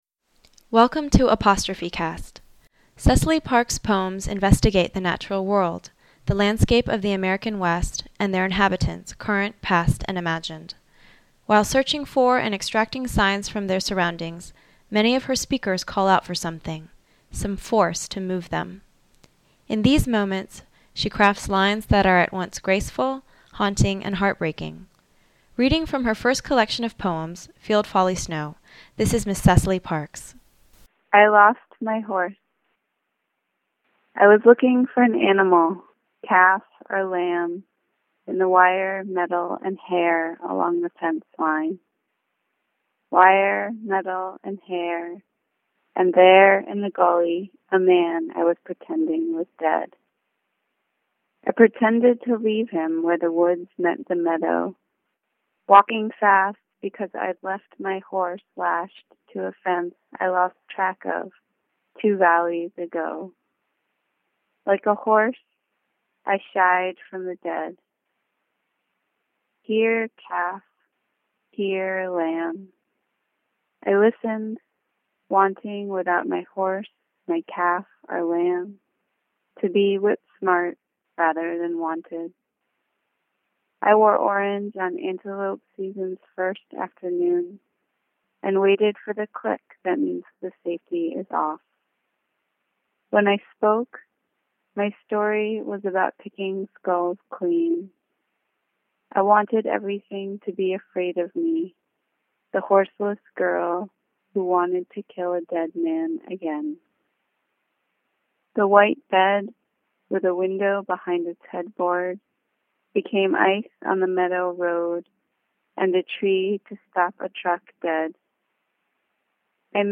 Poetry Reading